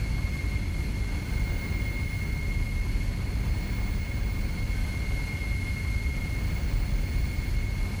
pgs/Assets/Audio/Sci-Fi Sounds/Mechanical/Engine 6 Loop.wav at 7452e70b8c5ad2f7daae623e1a952eb18c9caab4
Engine 6 Loop.wav